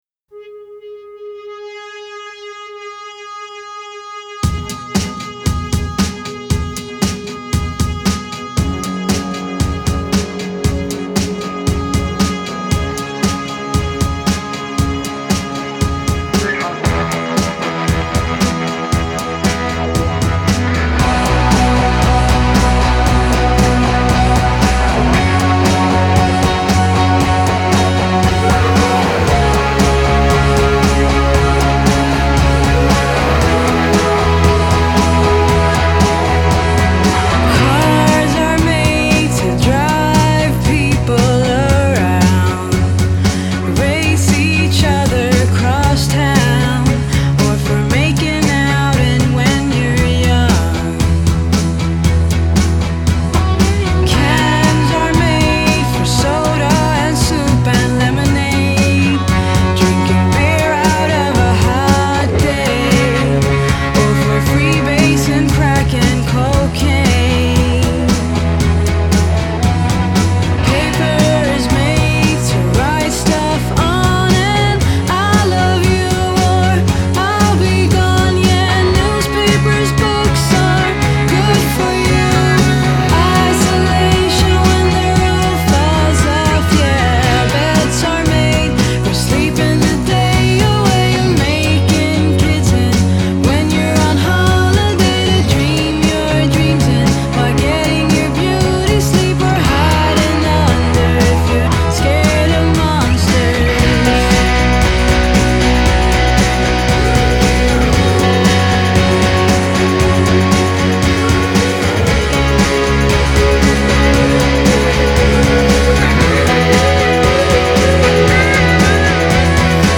Genre: Indie Pop, Rock, Female Vocal